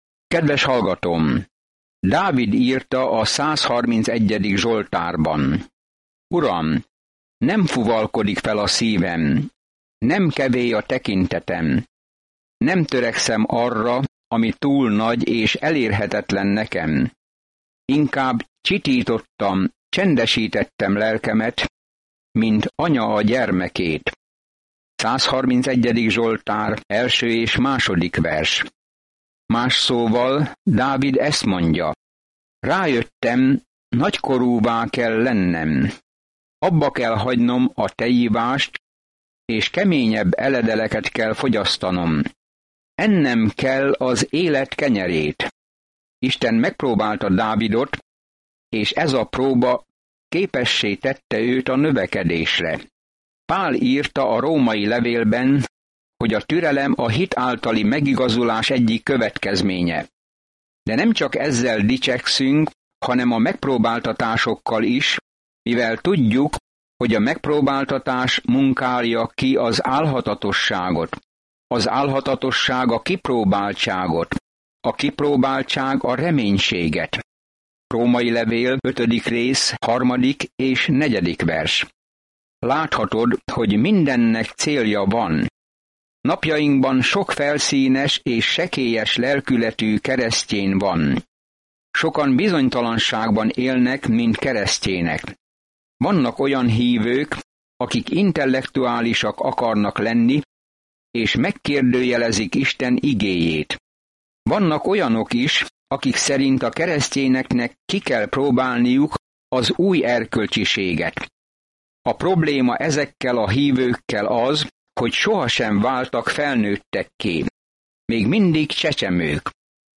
Szentírás Jakab 1:5-12 Nap 1 Olvasóterv elkezdése Nap 3 A tervről Ha hívő vagy Jézus Krisztusban, akkor tetteidnek tükrözniük kell az új életedet; tegye hitét tettekre. Napi utazás Jakabon keresztül, miközben hallgatod a hangtanulmányt, és olvasol válogatott verseket Isten szavából.